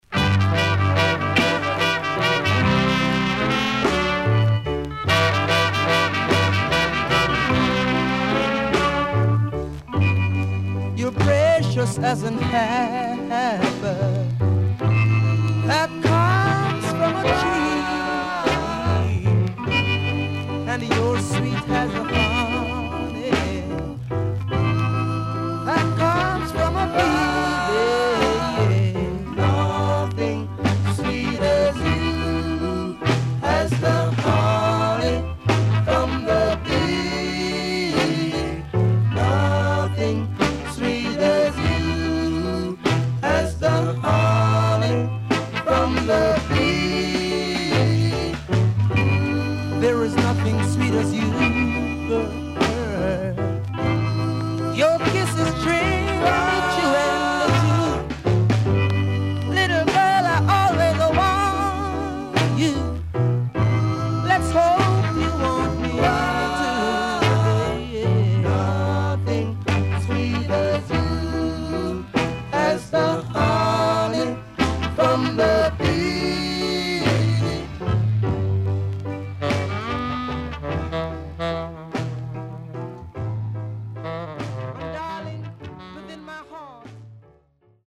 Nice Rocksteady Vocal.Ridies
SIDE A:少しチリノイズ入ります。